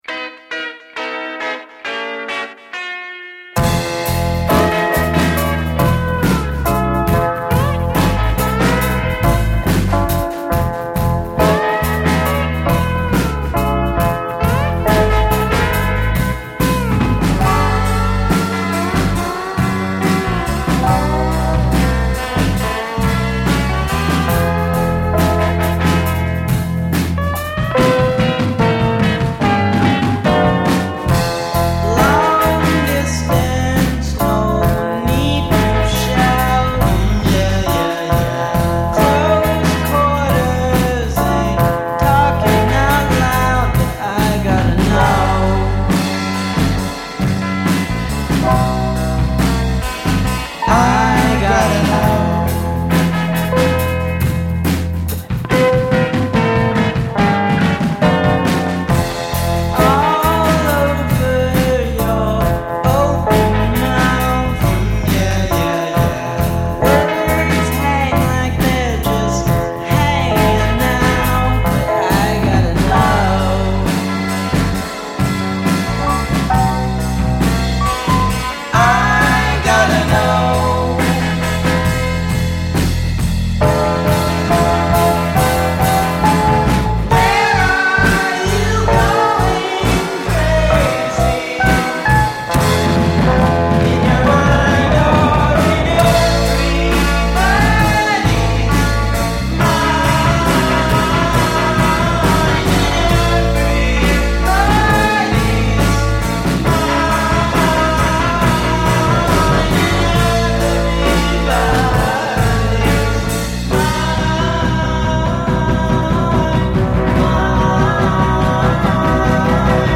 retro sounds of the 60’s and 70’s